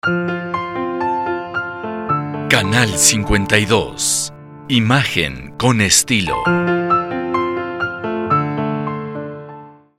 Locutor profesional con veinte años de experiencia.
Sprechprobe: Industrie (Muttersprache):